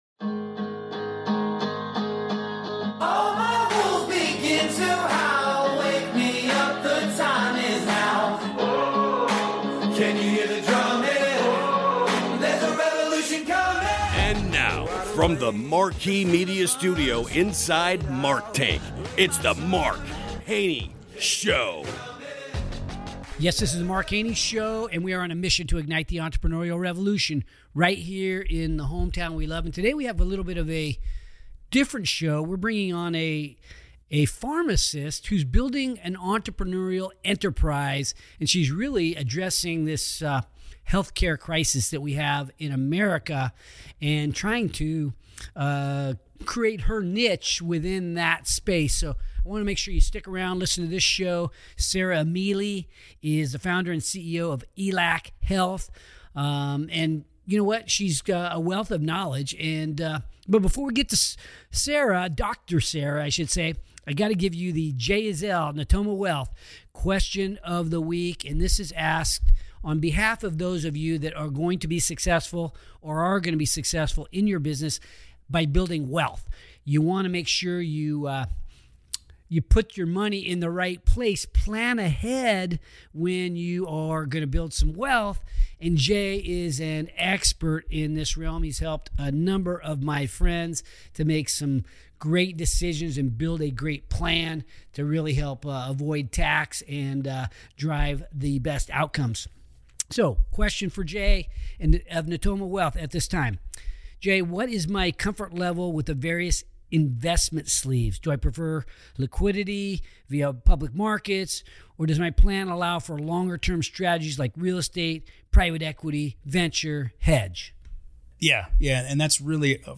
Brace yourself for an enlightening conversation